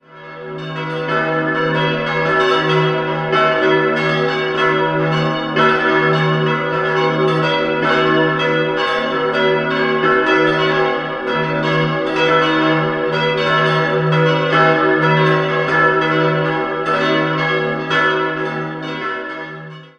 5-stimmiges erweitertes Salve-Regina-Geläute: e'-gis'-h'-cis''-e'' Die zweitgrößte Glocke wurde 1850 von der Gießerei Spannagl gegossen, alle anderen stammen aus der Werkstätte von Karl Czudnochwosky und entstanden im Jahr 1950.